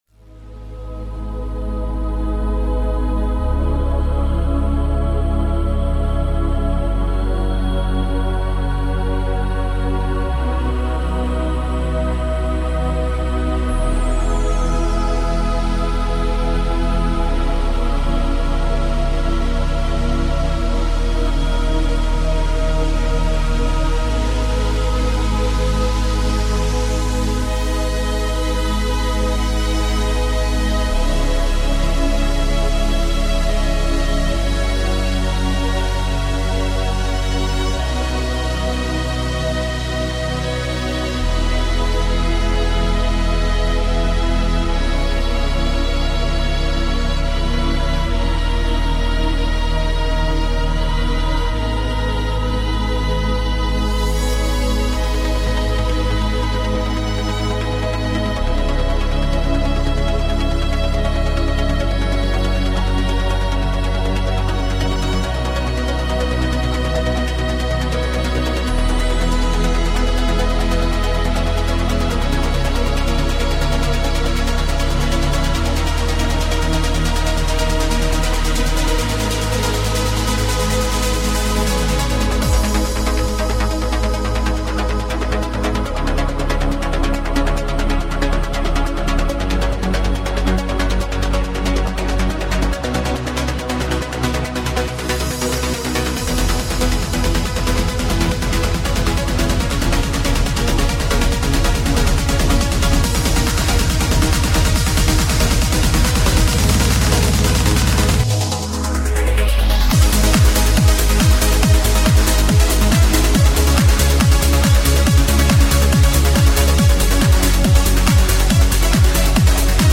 - UPLIFTING TRANCE